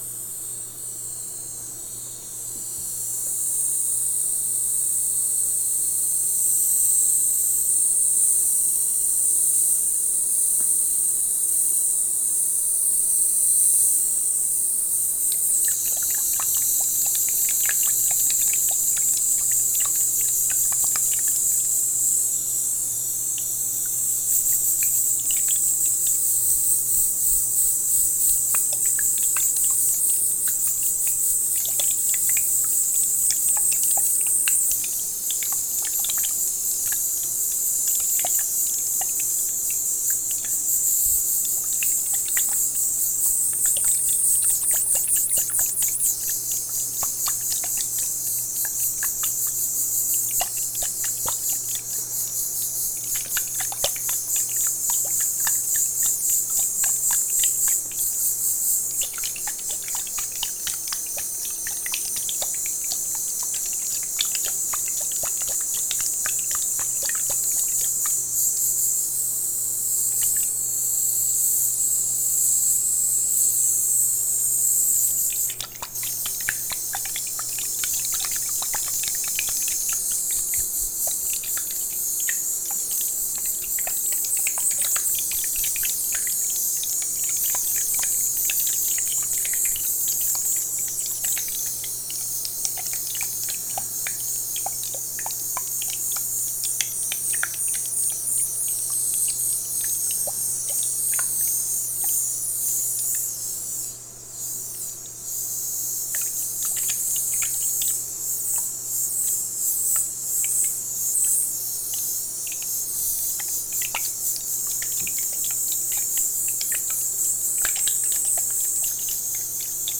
Water Vapor & Liquid Sounds | TLIU Studios
Category: ASMR Mood: Relax Editor's Choice